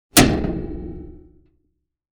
Lawn Mower, Lever Movement 3 Sound Effect Download | Gfx Sounds
Lawn-mower-lever-movement-3.mp3